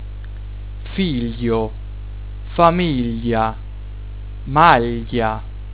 2.) - gli - (figlio, famiglia, maglia)
[ lj ]